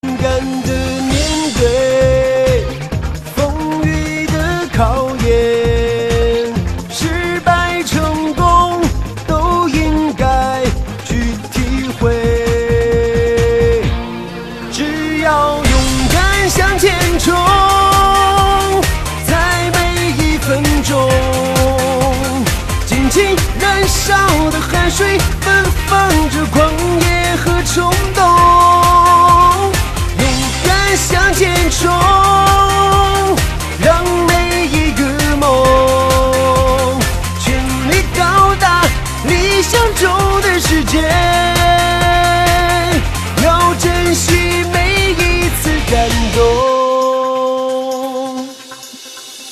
M4R铃声, MP3铃声, 华语歌曲 42 首发日期：2018-05-15 22:43 星期二